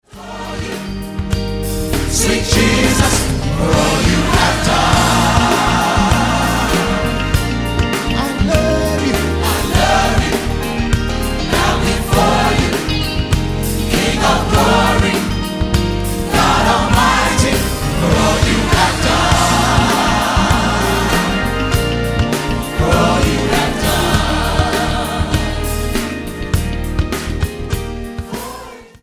STYLE: Gospel